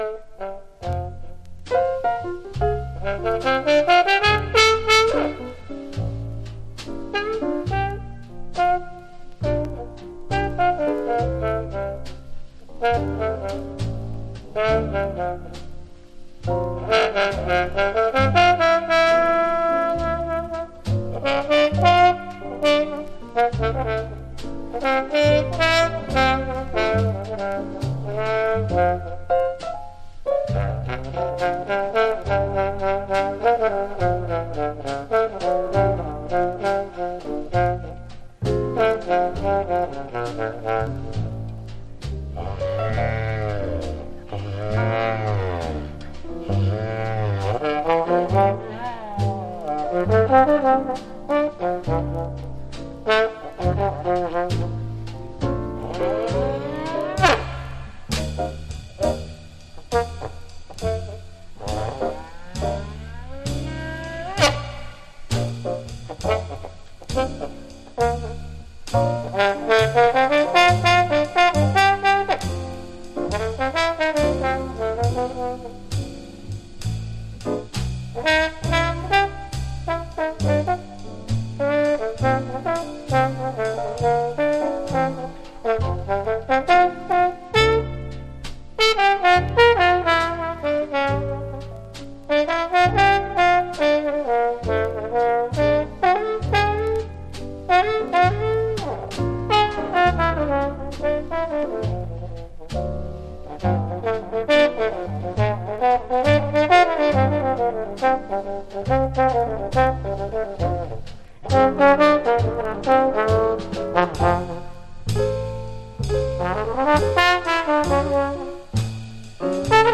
（小傷により少しチリ、プチ音ある曲あり）
Genre US JAZZ